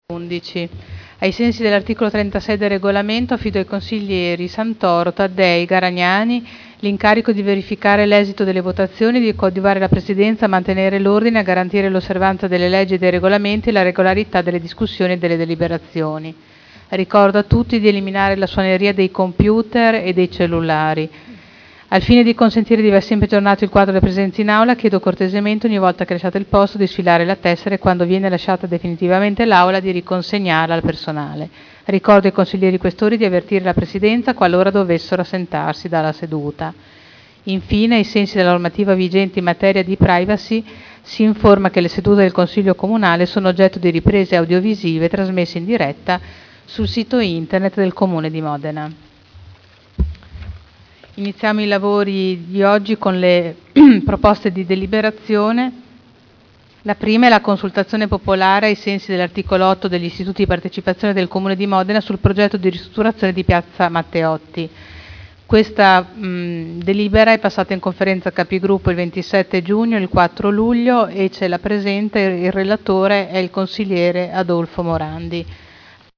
Il Presidente Caterina Liotti apre i lavori del Consiglio e passa la parola al Consigliere Morandi per la prima delibera in discussione.